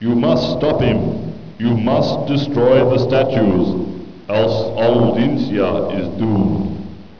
1 channel
ghost5.wav